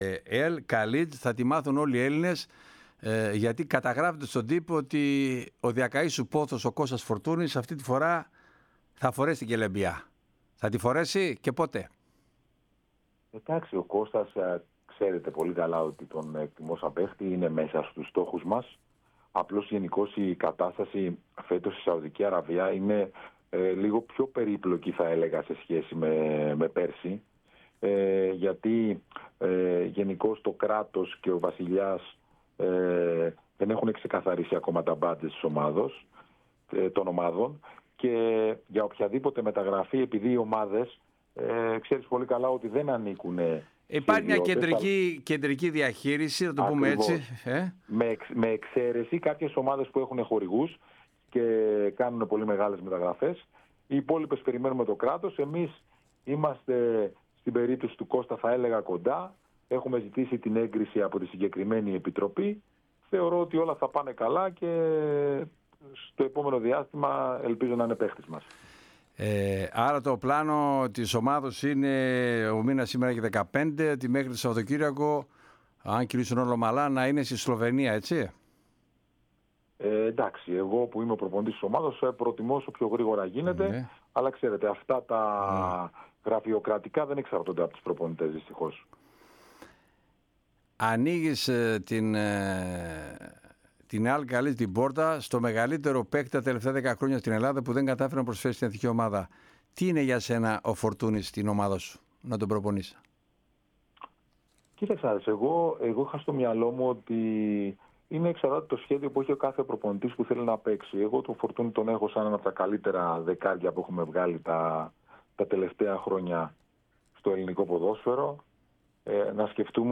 Ακούστε όσα είπε στην ΕΡΑ ΣΠΟΡ για τον Κώστα Φορτούνη ο Γιώργος Δώνης: